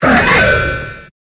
Cri d'Exagide dans Pokémon Épée et Bouclier.